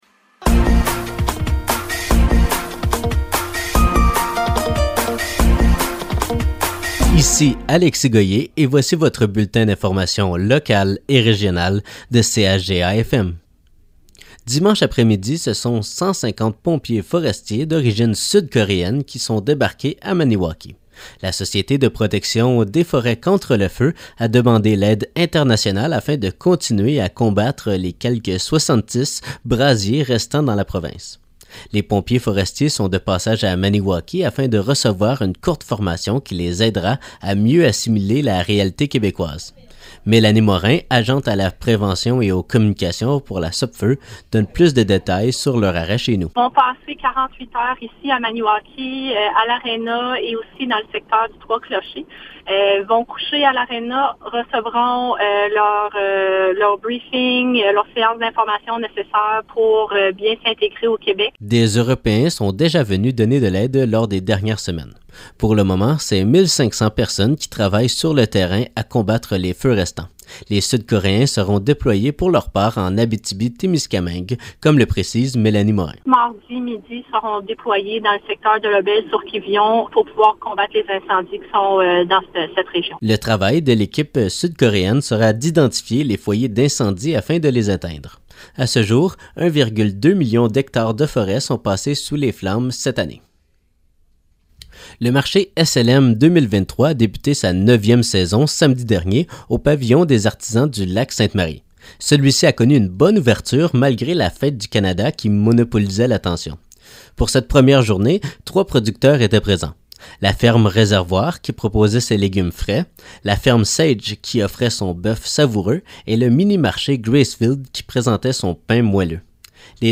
Nouvelles locales - 3 juillet 2023 - 12 h